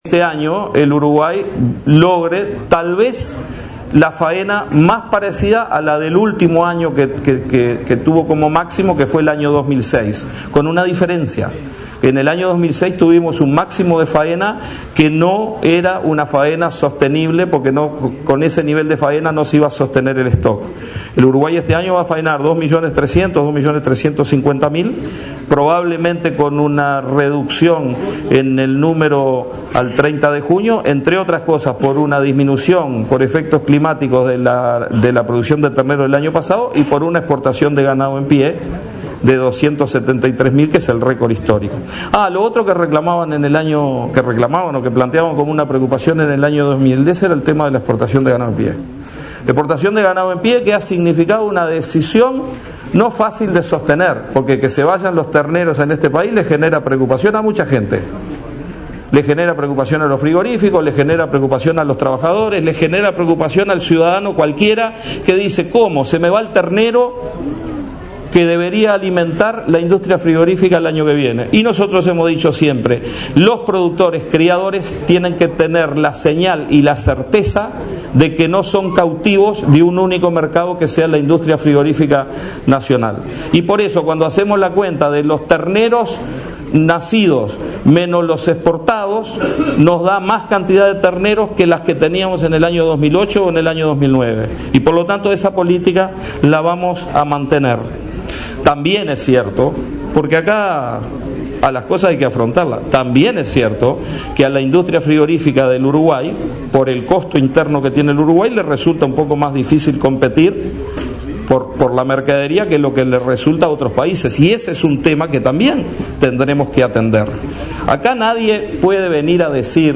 “Probablemente este año se faenen casi 2,4 millones de animales”, una de las más exitosas de su historia, destacó el ministro de Ganadería, Agricultura y Pesca, Tabaré Aguerre, en la clausura del 100.º Congreso de la Federación Rural. Indicó, además, que el gobierno ha respondido exitosamente a desafíos como la brucelosis y el abigeato y ha desarrollado la trazabilidad bovina y la instalación de comisiones de seguridad rural.